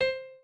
pianoadrib1_49.ogg